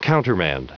Prononciation du mot countermand en anglais (fichier audio)
Prononciation du mot : countermand